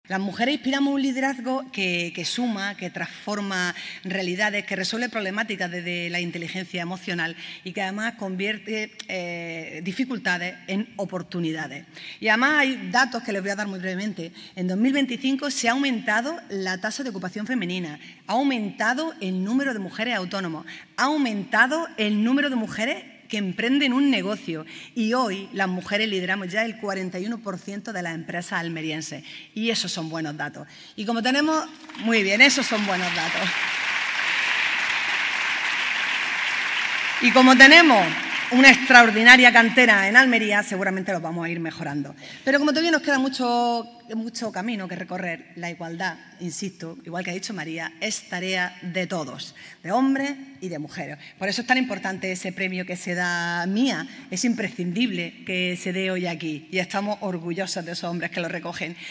La alcaldesa, María del Mar Vázquez, destaca la apuesta municipal por «impulsar, visibilizar y potenciar el talento femenino de las más de 105.000 mujeres que vivimos en Almería»
El Auditorio Maestro Padilla acogió anoche la celebración de los IV Premios MIA, una gala que reunió a más de 500 personas y que volvió a poner en valor el talento, la trayectoria y el impacto de mujeres referentes en distintos ámbitos profesionales de la provincia.
En sus palabras de bienvenida, la alcaldesa ha destacado la apuesta municipal por «impulsar, visibilizar y potenciar el talento femenino de las más de 105.000 mujeres que vivimos en Almería».